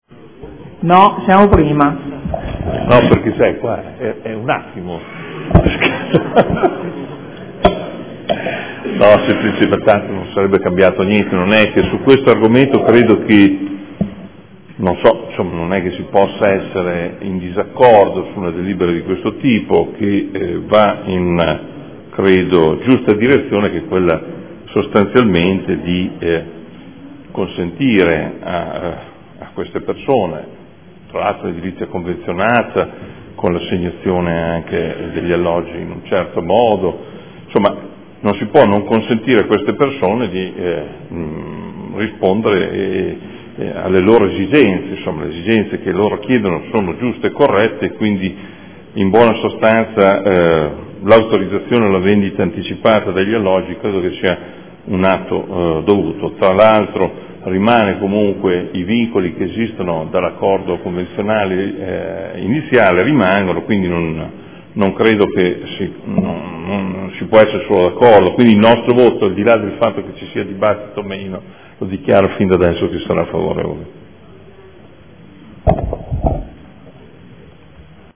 18/12/2014 Dichiarazione di voto.